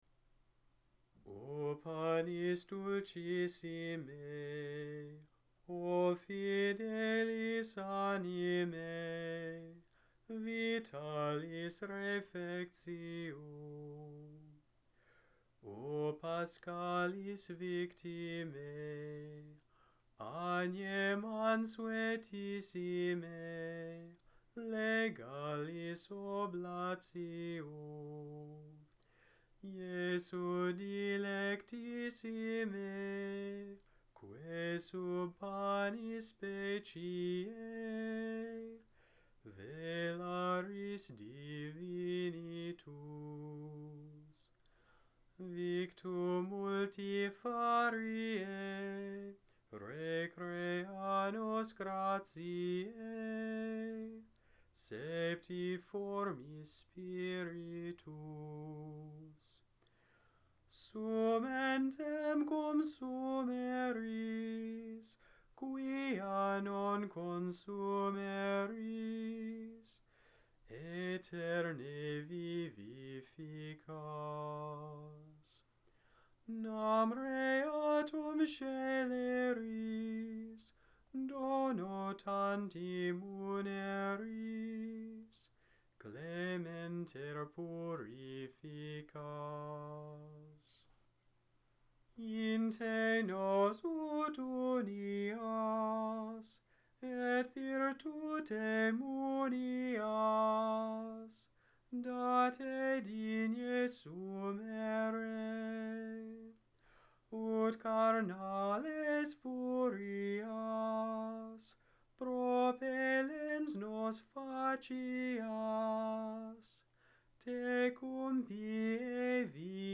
Gregorian chant audios